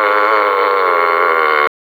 Burping In Public